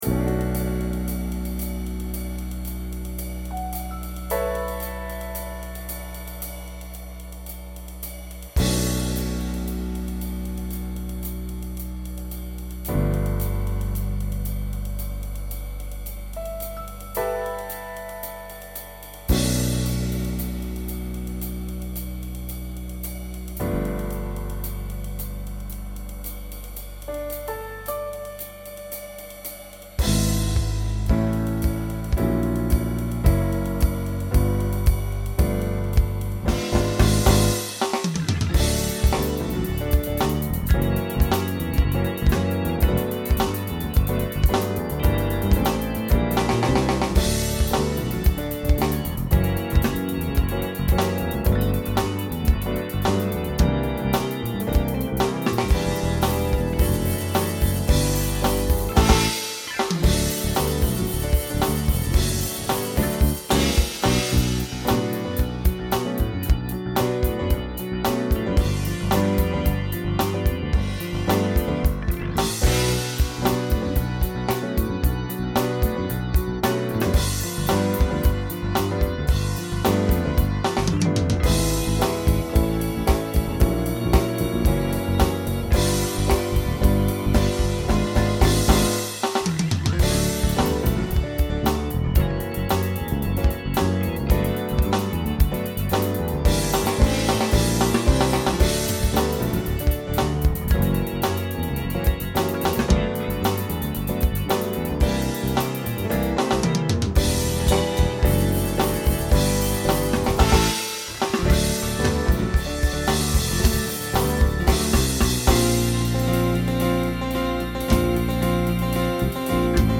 SATB Instrumental combo
Broadway/Film
Mid-tempo